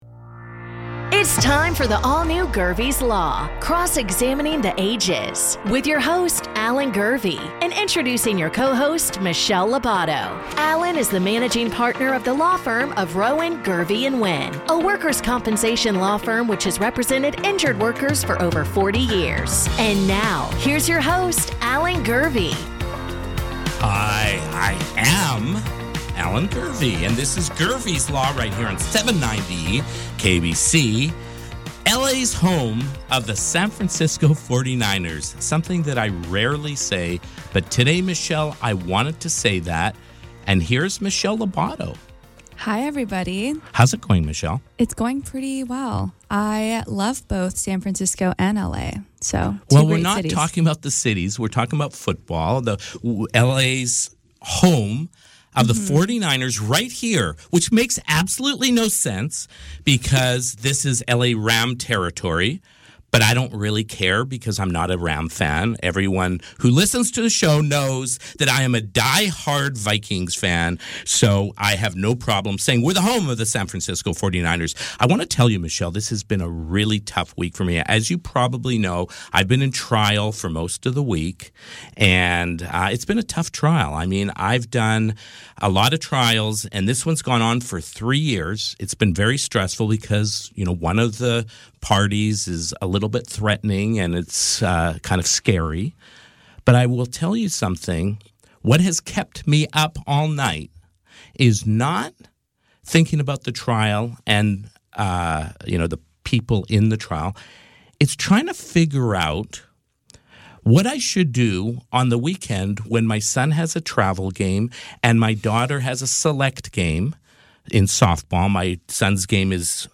Don't miss our exclusive interview with three-time Super Bowl champion Ed McCaffrey!